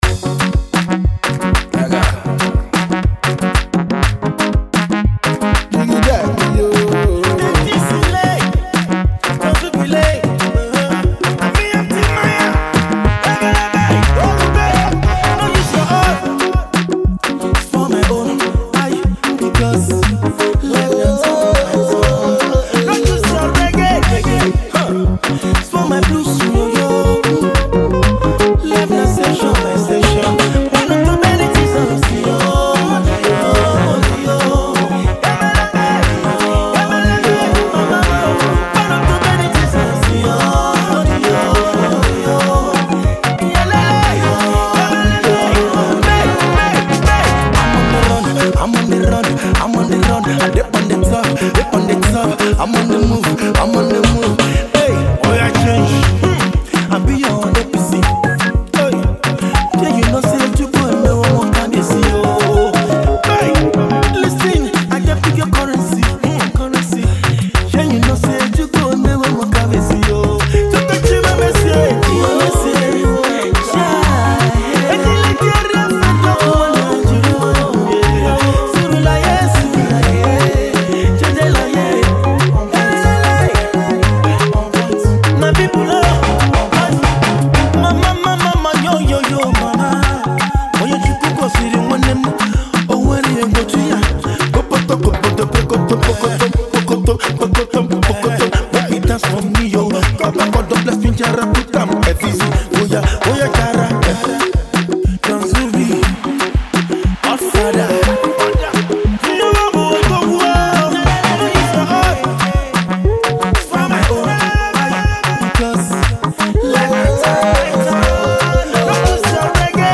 Afro-pop infectious track